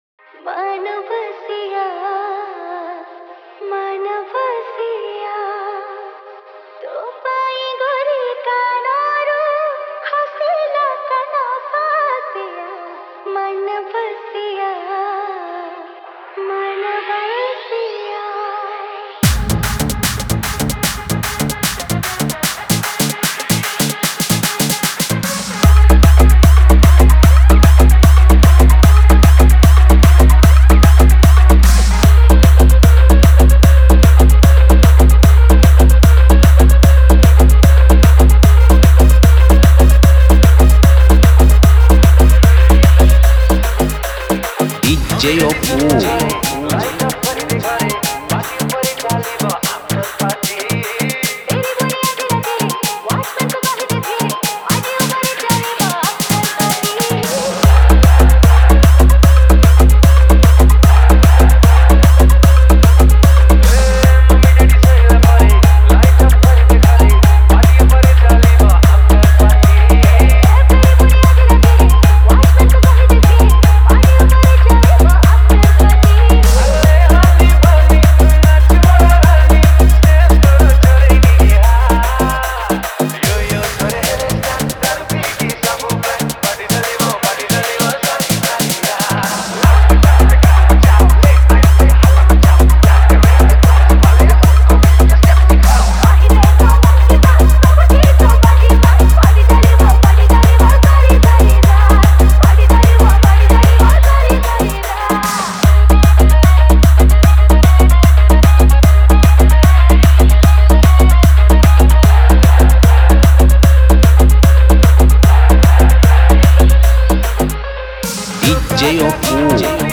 Odia Tapori Power Dance Mix